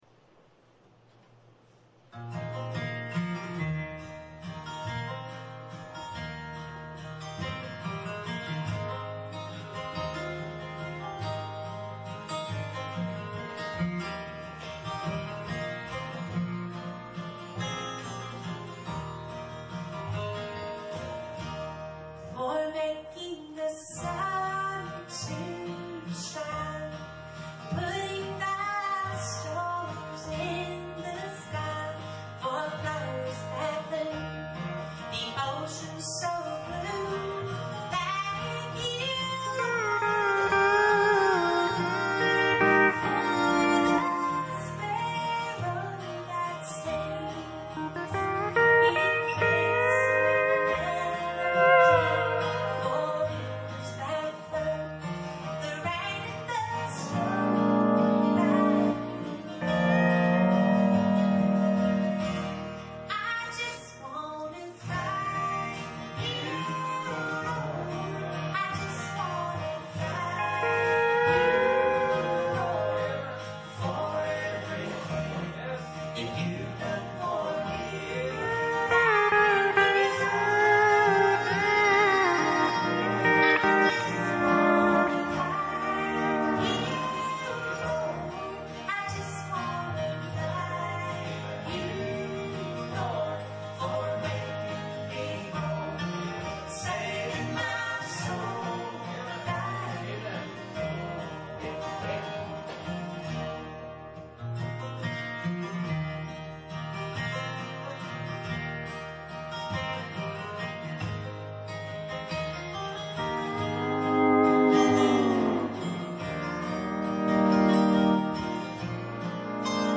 I recently remixed all the songs in stereo.
Louder guitar